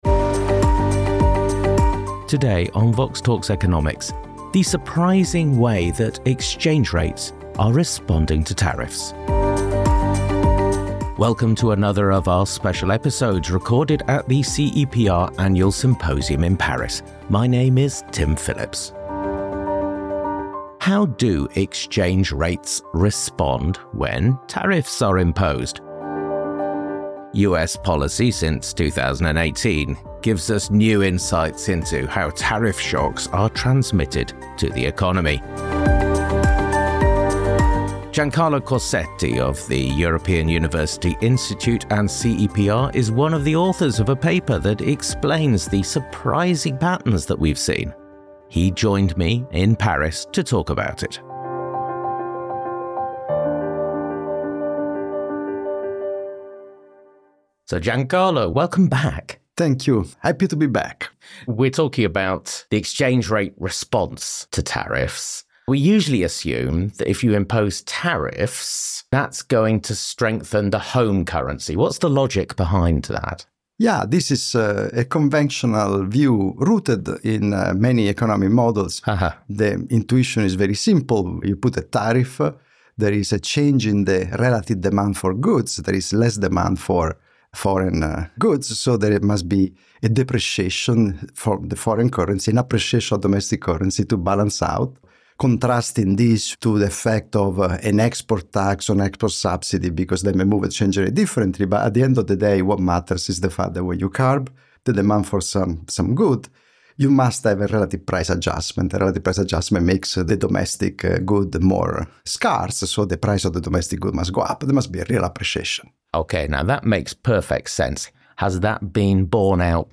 In another episode recorded at the CEPR Annual Symposium in Paris